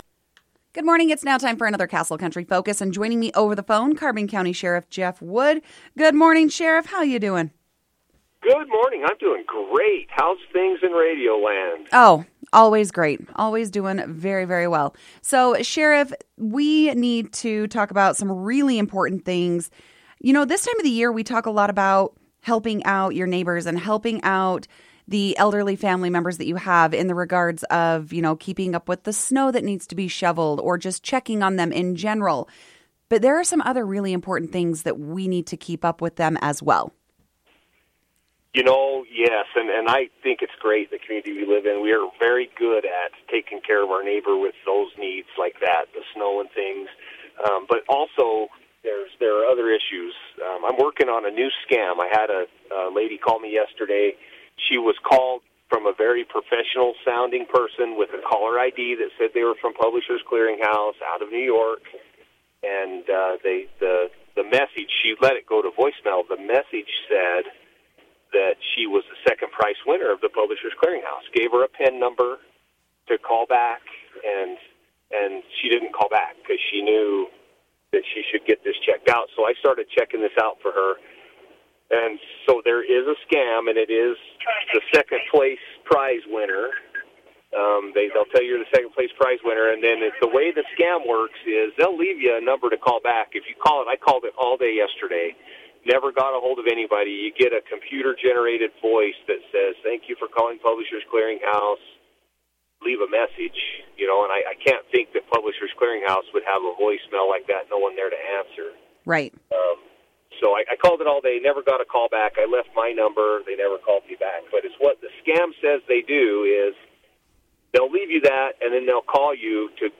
There is a phone scam making its way around the area and it’s causing many folks to question the calls. Carbon County Sheriff Jeff Wood took time on his weekly update to discuss the latest scam that involves Publisher’s Clearing House.